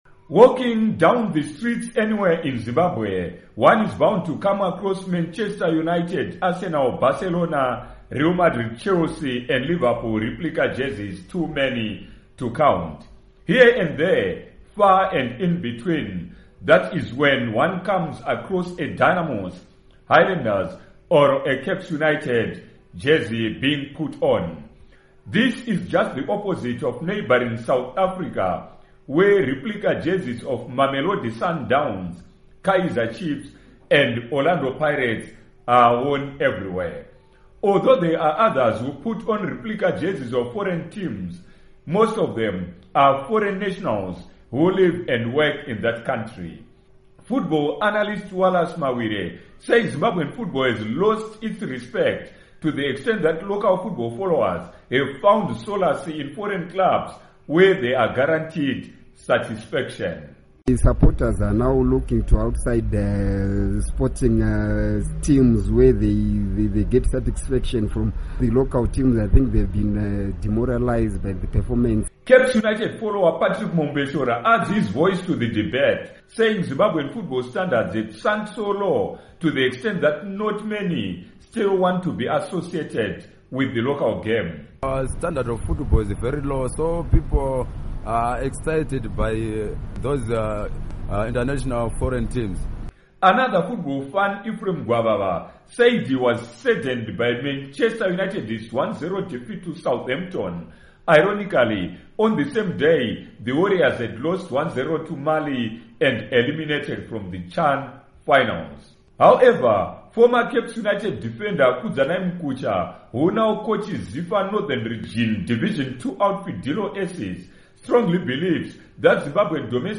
Report on Replica Jerseys